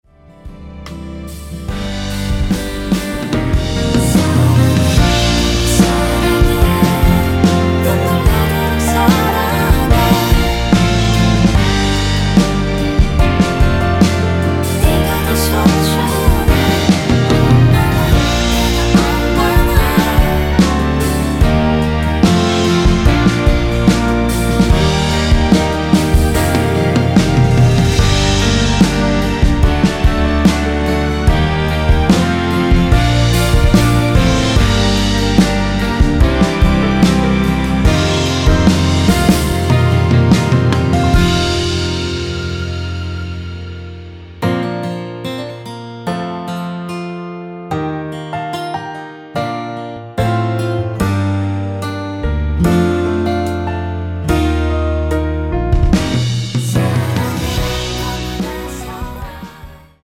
원키 코러스 포함된 MR입니다.
앞부분30초, 뒷부분30초씩 편집해서 올려 드리고 있습니다.